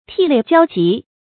涕泪交集 tì lèi jiāo jí 成语解释 眼泪和鼻涕一起流着。形容悲痛到了极点。